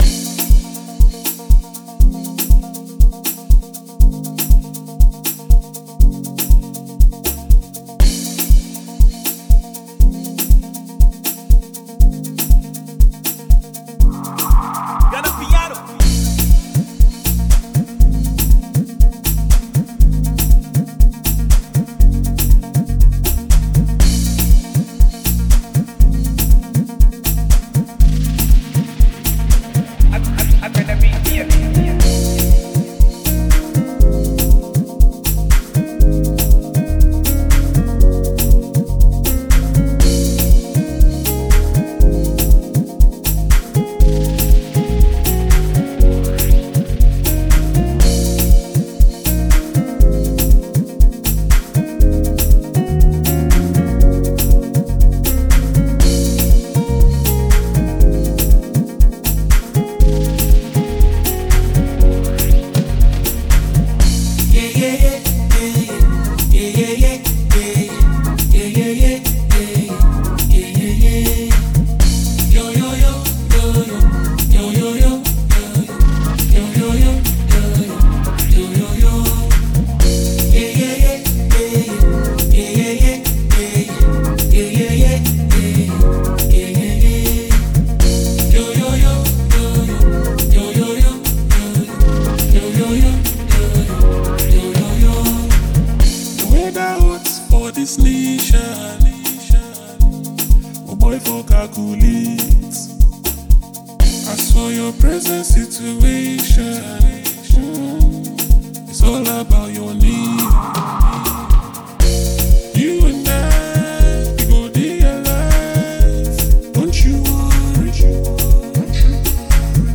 a talented Ghanaian duo.